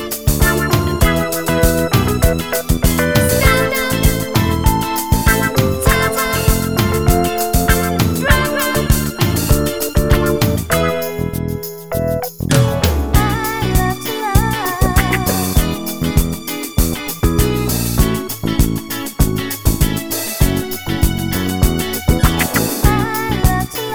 no kit bass or main guitar Disco 3:02 Buy £1.50